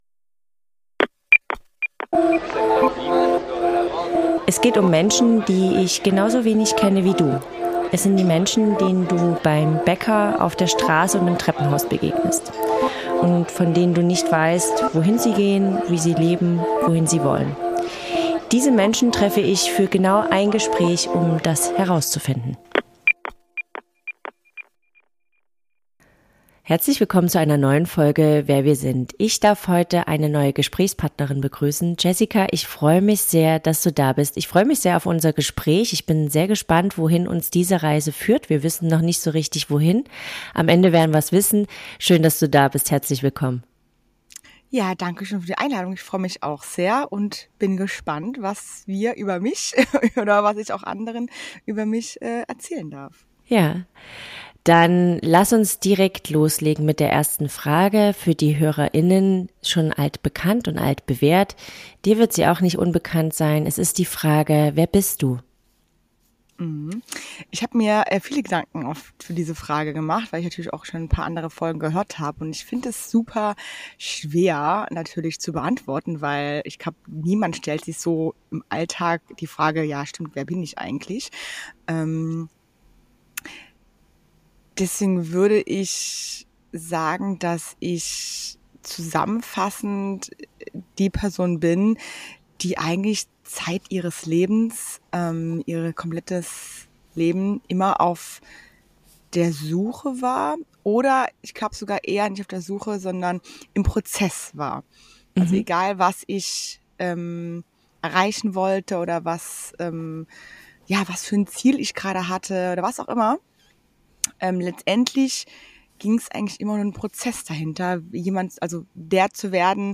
Ein Gespräch über Krankheit, Verletzlichkeit und die überraschende Kraft, die entsteht, wenn man beginnt, Glück und Authentizität nicht mehr aufzuschieben.